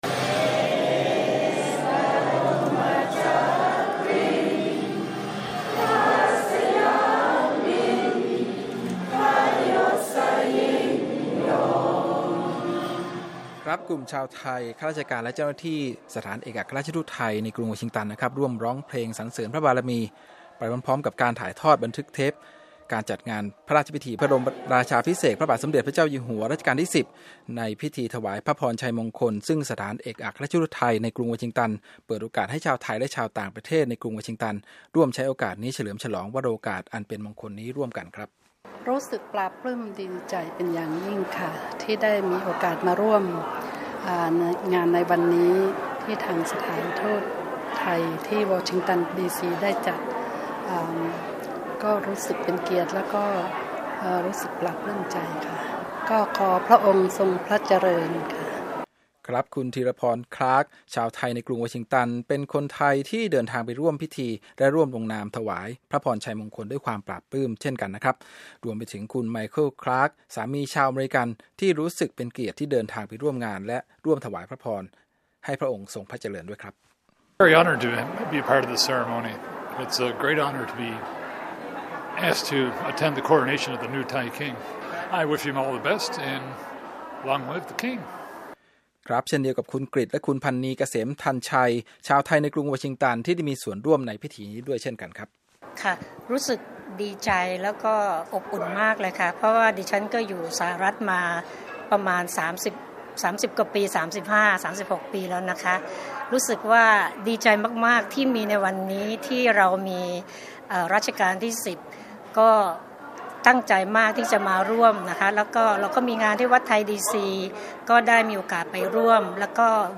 กลุ่มชาวไทย ข้าราชการ และเจ้าหน้าที่สถานเอกอัครราชทูตไทย ในกรุงวอชิงตัน ร่วมกันร้องเพลงสรรเสริญพระบารมี ไปพร้อมๆกับชมการถ่ายทอดเทปบันทึกภาพการจัดงานพระราชพิธีบรมราชาภิเษก พระบาทสมเด็จพระเจ้าอยู่หัว รัชกาลที่ 10 ในพิธีถวายพระพรชัยมงคล ที่สถานเอกอัครทูตเปิดโอกาสให้ชาวไทยและชาวต่างประเทศ ในกรุงวอชิงตันได้ร่วมใช้โอกาสนี้เฉลิมฉลองวโรกาสอันเป็นมงคลนี้ร่วมกัน